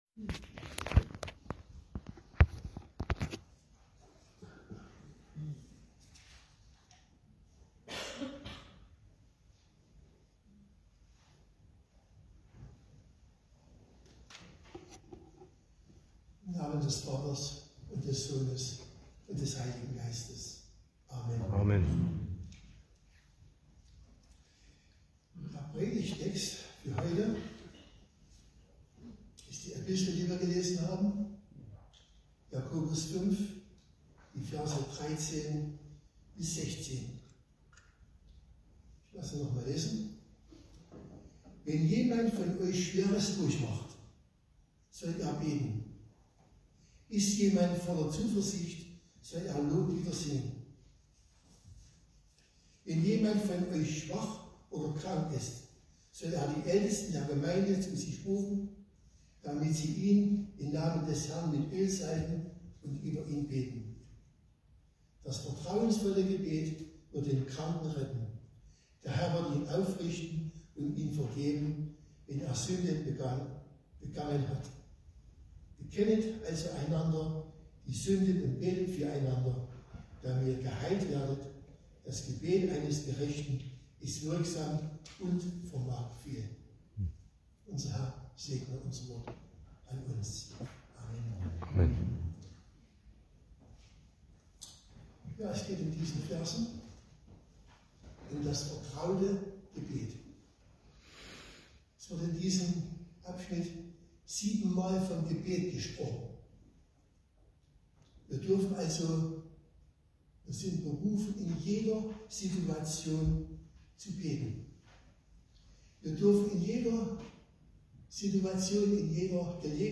Passage: Jakobus 5; 13-16 Gottesdienstart: Predigtgottesdienst Wildenau « Bittet den Herrn der Ernte